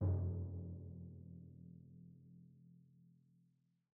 timpani7d-hit-v2-rr1-main.mp3